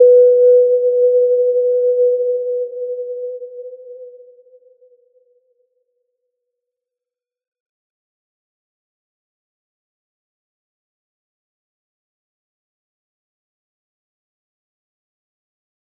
Little-Pluck-B4-p.wav